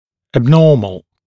[æb’nɔːml][эб’но:мл]анормальный, отклоняющийся от нормы, патологический